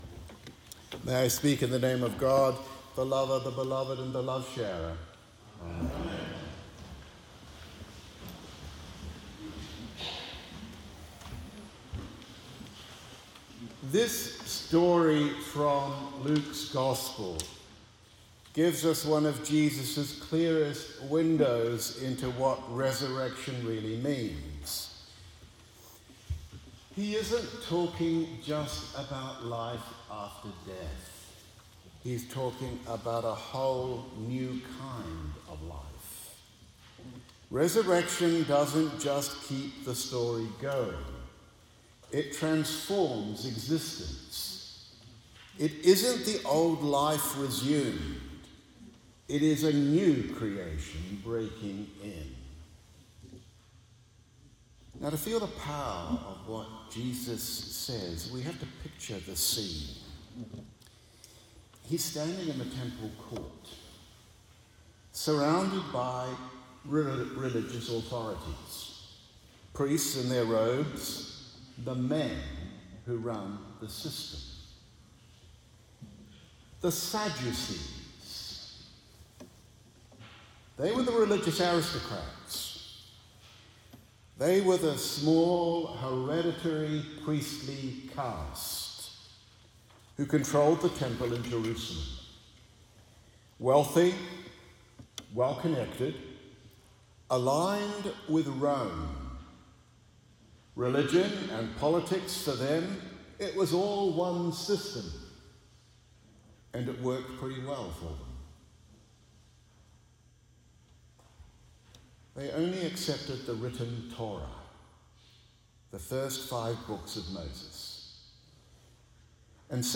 Sermon on Luke 20:27–38 — “The God of the Living”